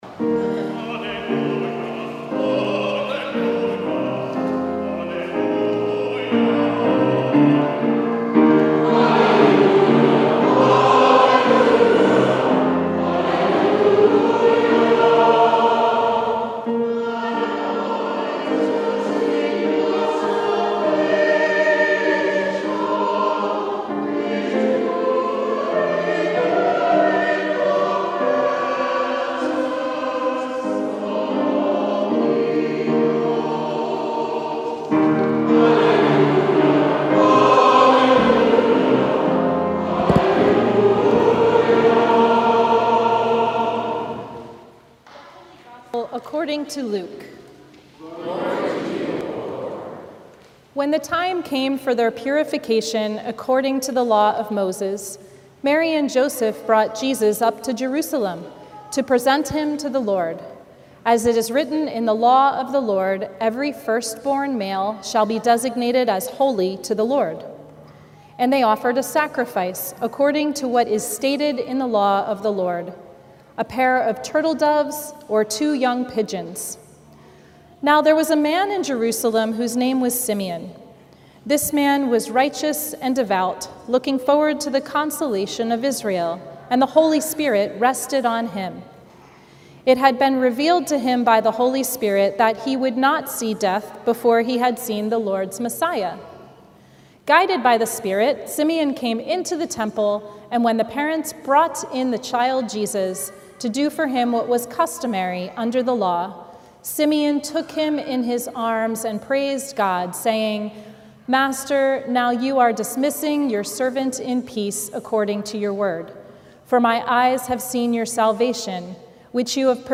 Sermon from the Fifth Sunday after Pentecost